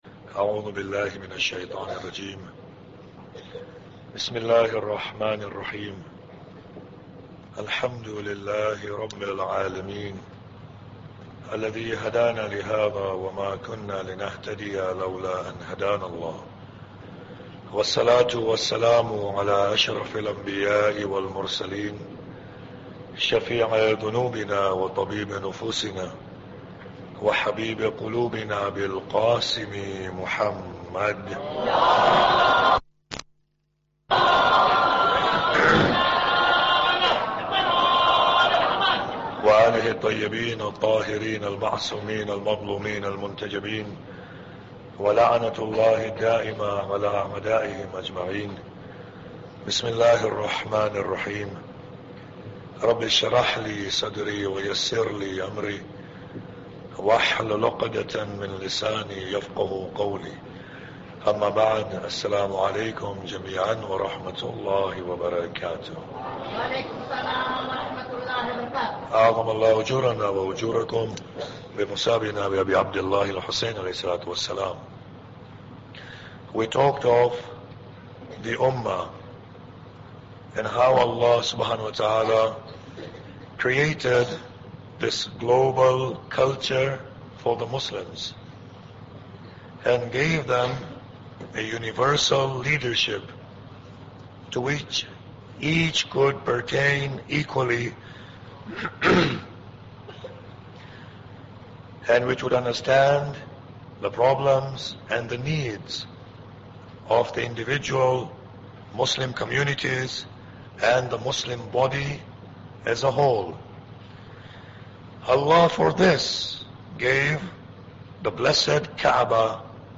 Muharram Lecture 7